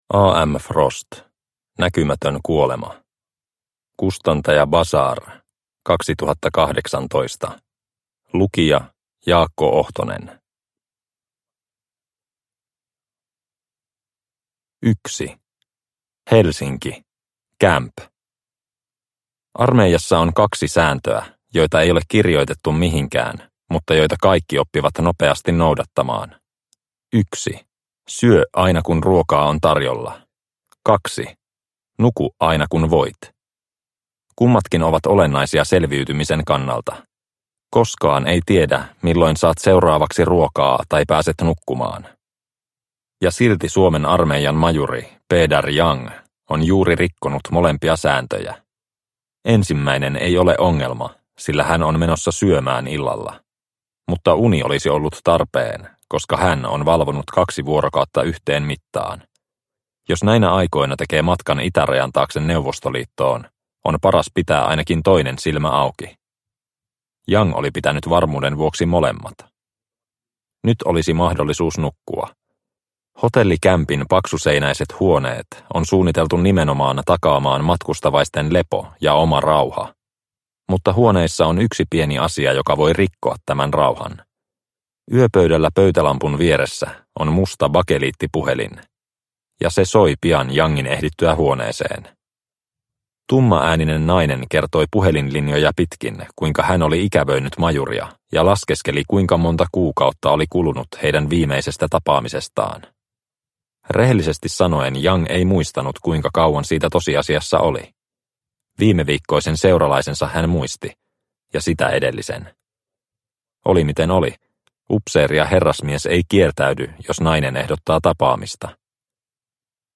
Näkymätön kuolema – Ljudbok – Laddas ner